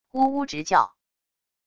呜呜直叫wav音频